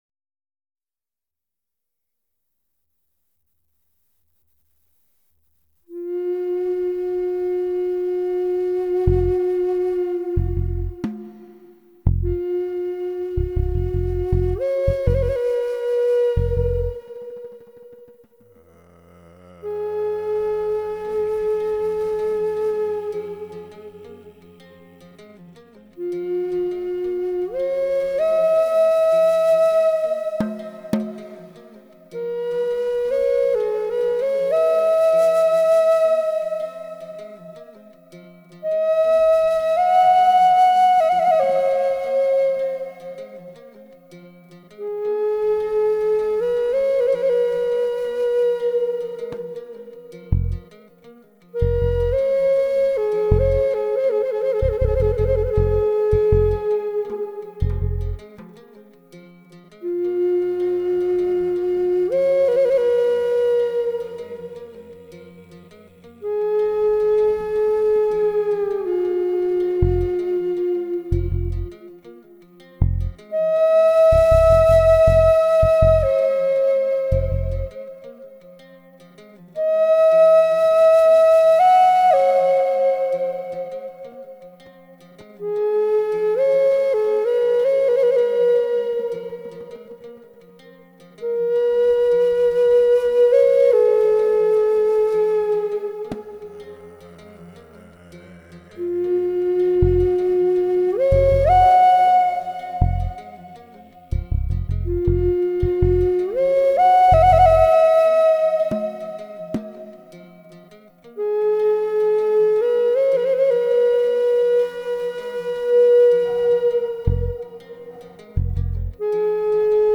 Many of you know that I play the Native American flute.
So tonight I tried my hand at some recording which was really fun since I’m not a Garage Band expert by any stretch of the imagination.  I had fun though and managed to create a short song that features one of my Navajo flutes…  Hoping you enjoy this brief few minutes of peace…